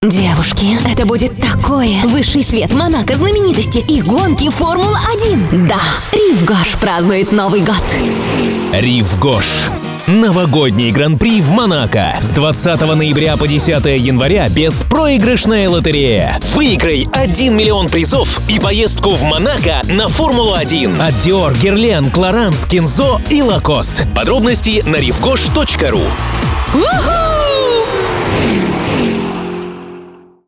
2 диктора, спецэффекты, паспорт